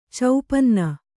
♪ caupanna